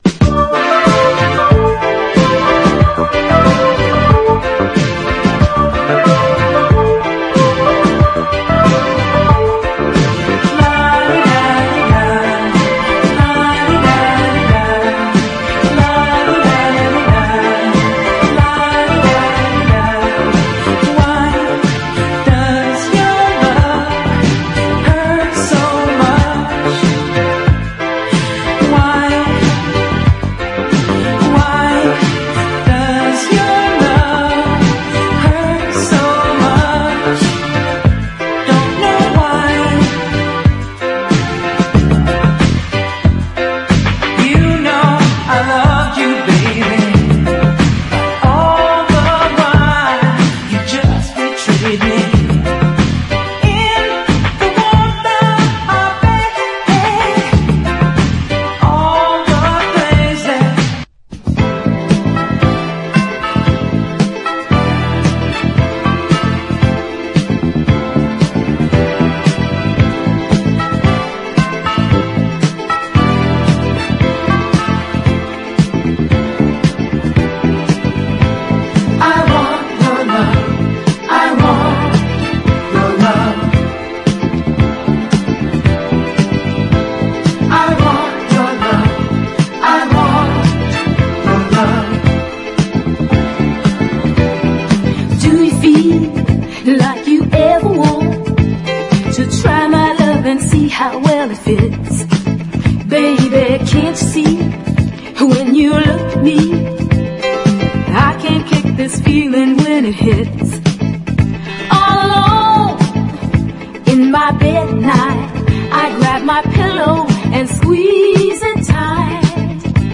ROCK / 70'S / O.S.T. / BLUES / SWAMP / PSYCHEDELIC
アーシーでブルージーなライヴ・ドキュメンタリー・サントラ！